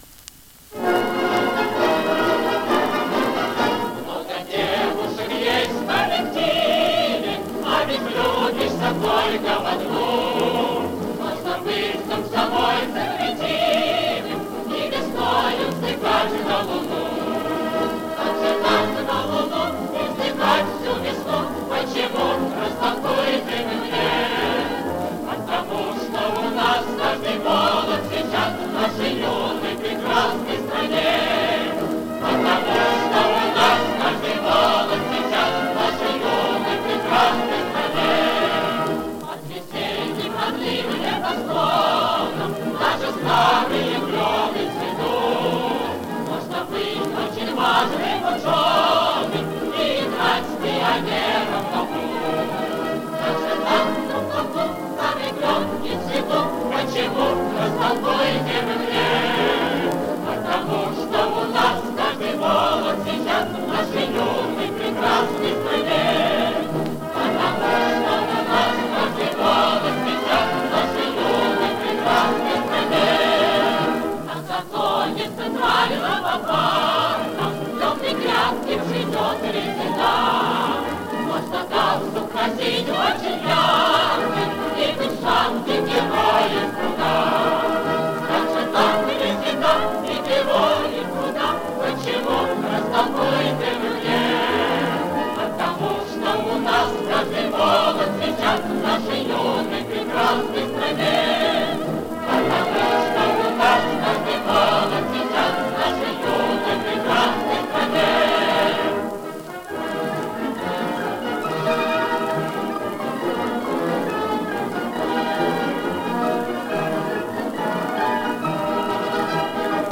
Послевоенная запись